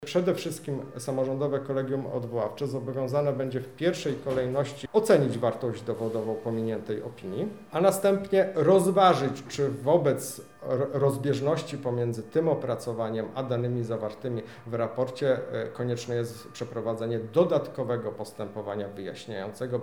Bartłomiej Pastuch– dodaje sędzia Bartłomiej Pastuch